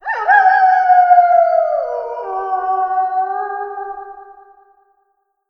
Dota_Wolf_Nachtanbruch.mp3